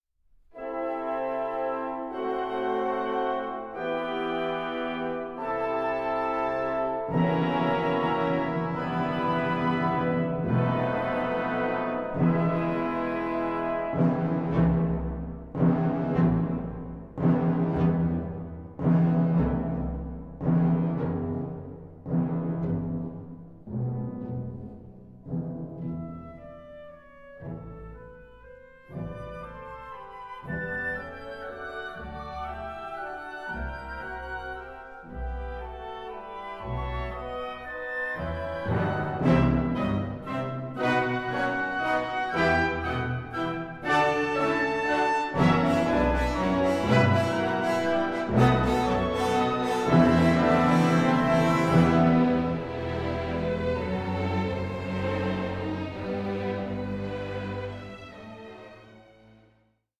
Allegro energico e passionato 9:52